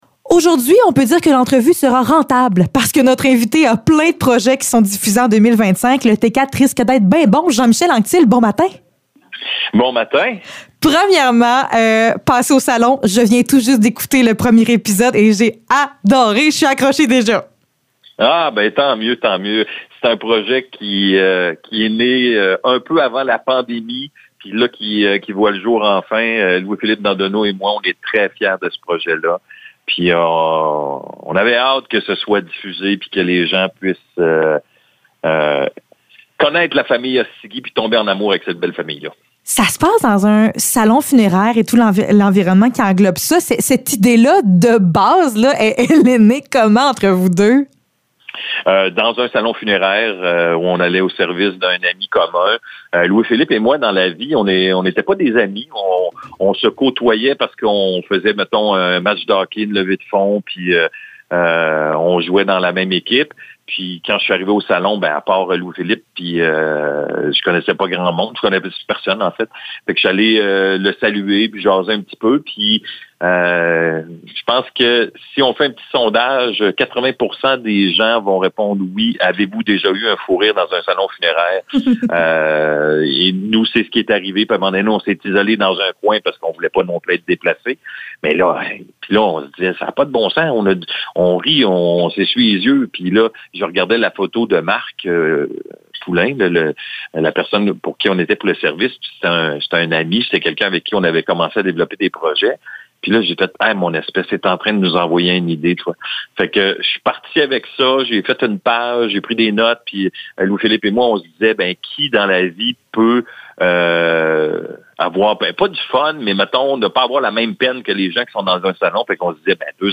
Entrevue avec Jean-Michel Anctil